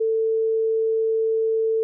anhören (Etwas höher)
440komma5_1.ogg